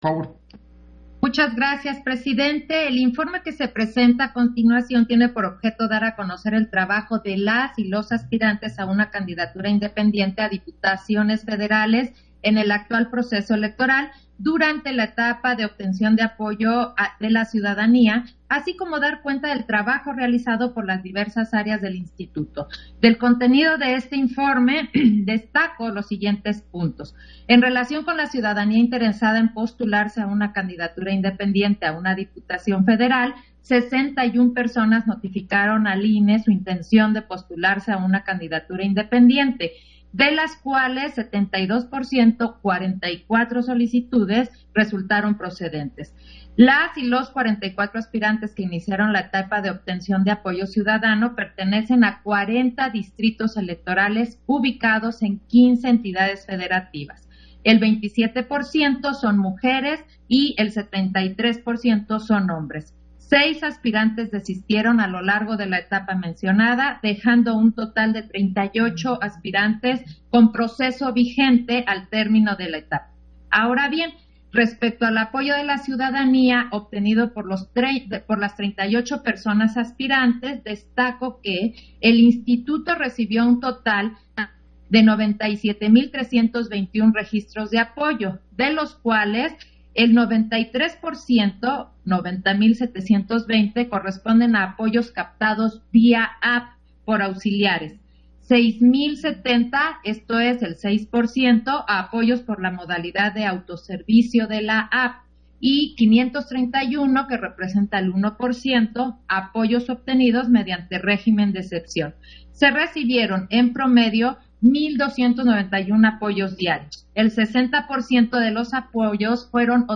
Intervención de Claudia Zavala, en el punto de la segunda Sesión Extraordinaria, relativo al Informe sobre la etapa de obtención de apoyo de la ciudadanía